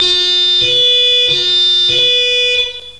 Sirenensignale/Martinshorn
Horn4.mp3